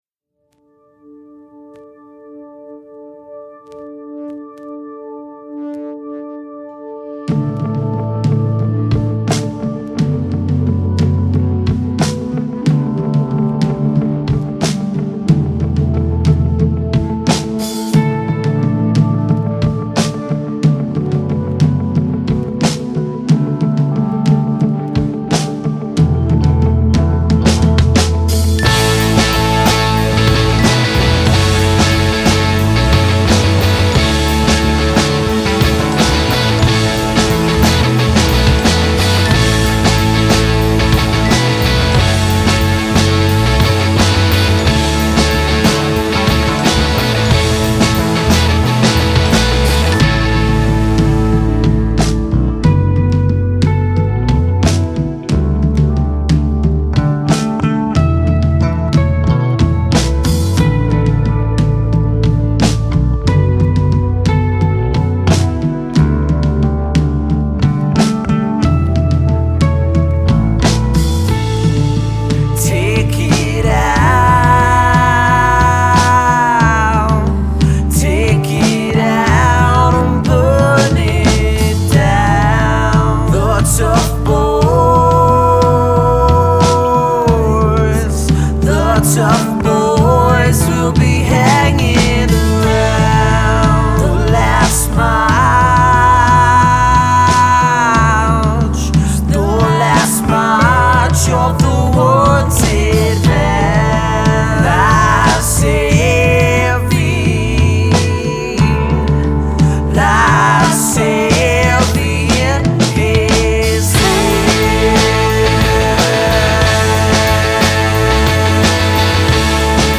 tracks one and four have more changes in pace